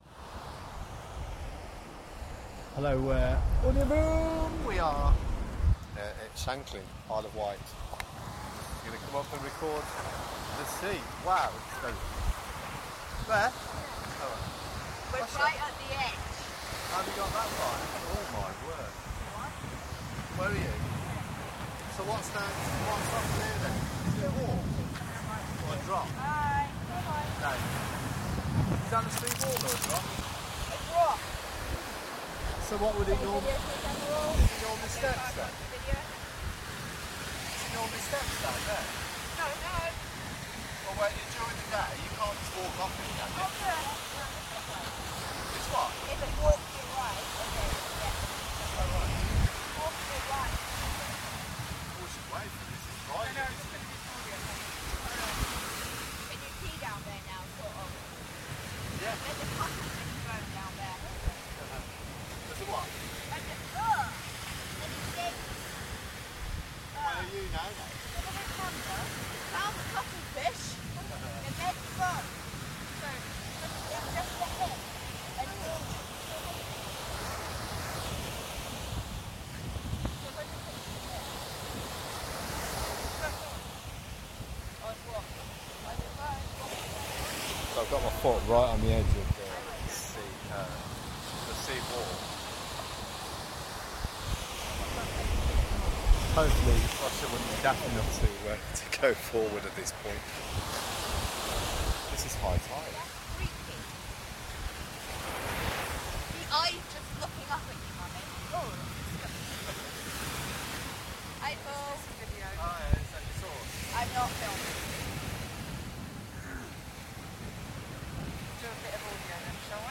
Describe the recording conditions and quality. high tide while night time beach walking at Sandown, Isle of Wight!